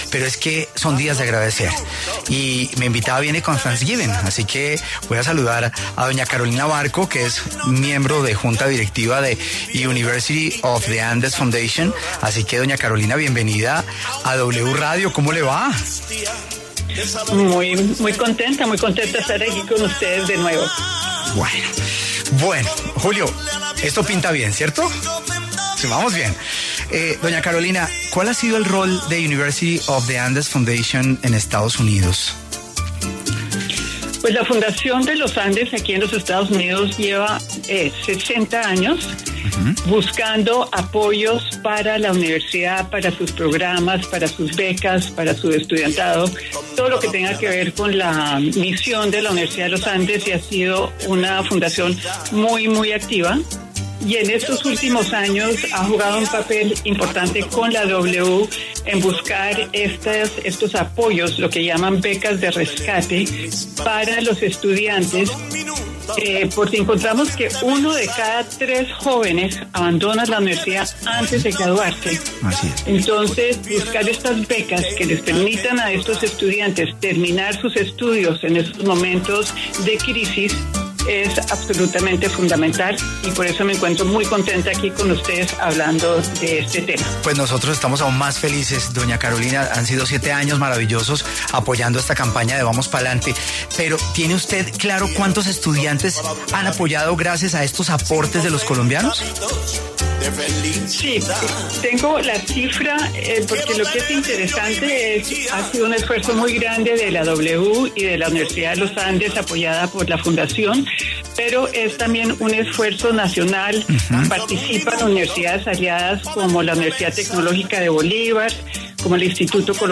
Carolina Barco, excanciller y miembro de la Junta Directiva de University of the Andes Foundation, conversó con La W sobre la importancia de la campaña Vamos Pa’ Lante.